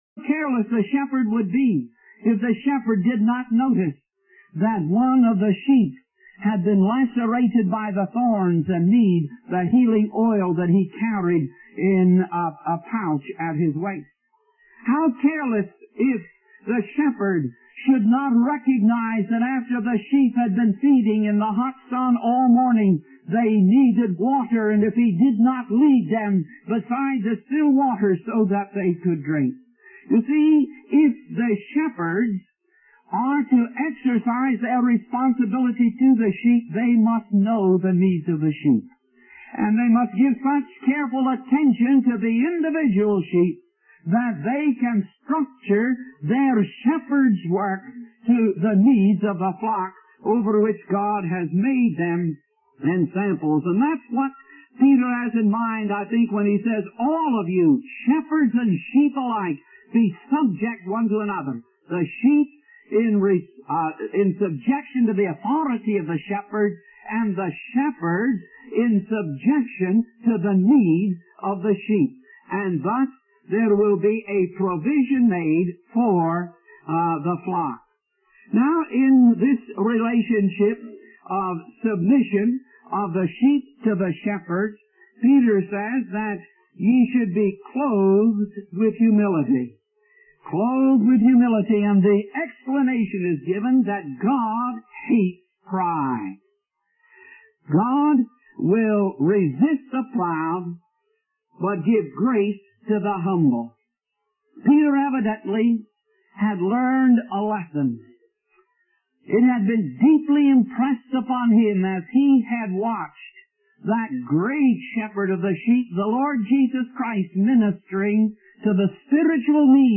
In this sermon, the Apostle Paul addresses the saints in Ephesus, comparing them to sheep in need of a shepherd and children in need of parental care.